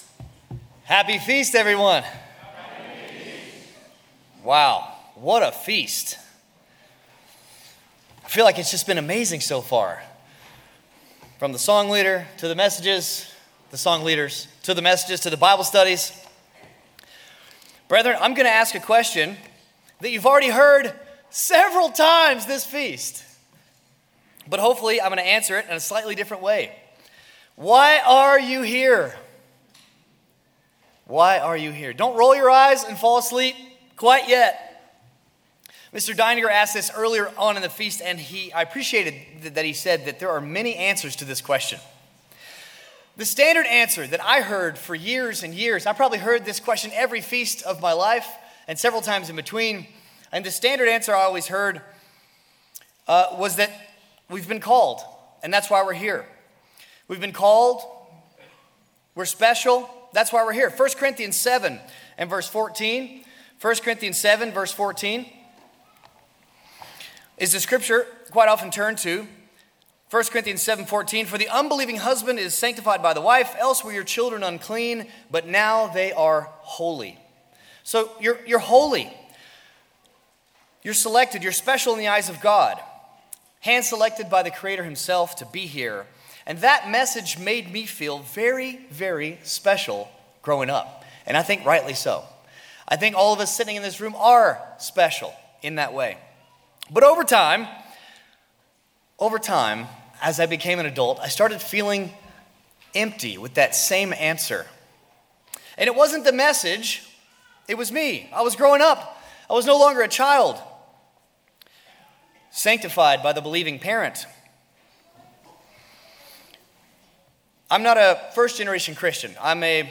Sermons
Given in Glacier Country, Montana 2025